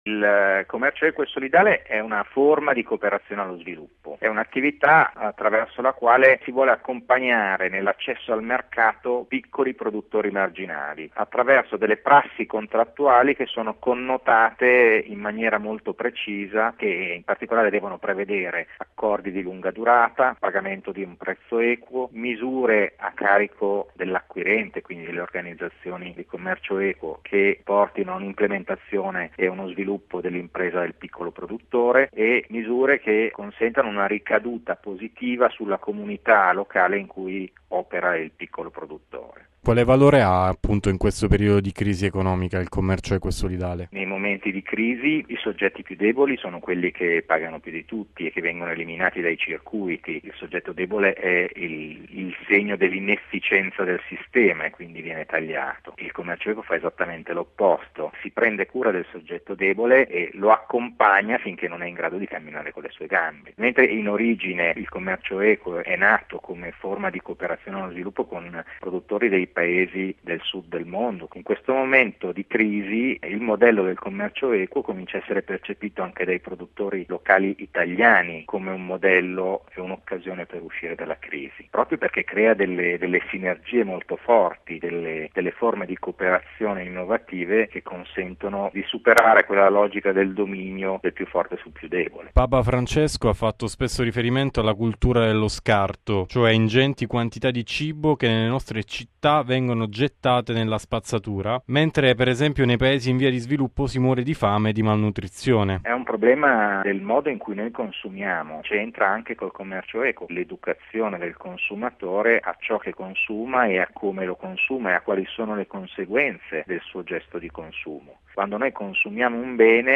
Bollettino Radiogiornale del 14/05/2016